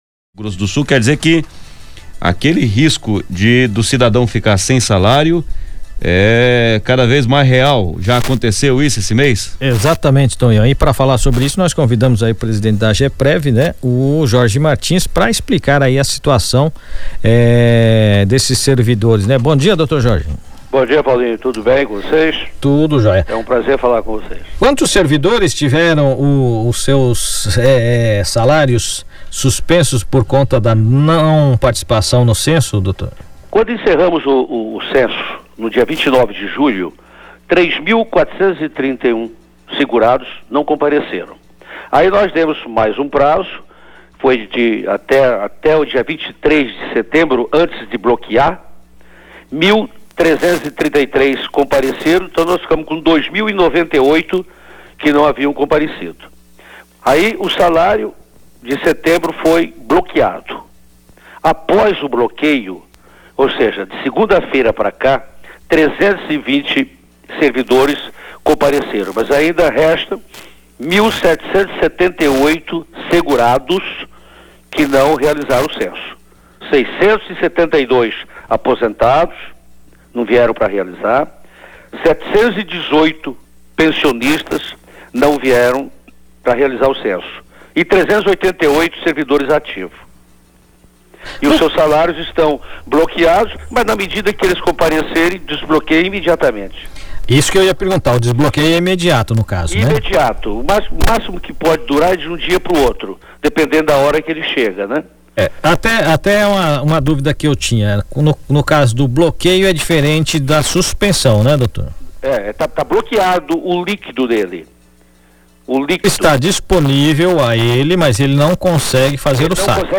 Diretor-presidente da Ageprev concede entrevista e orienta segurados; Ouça áudio
Rádio-006.m4a